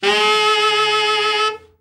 Index of /90_sSampleCDs/Giga Samples Collection/Sax/TENOR OVERBL
TENOR OB  15.wav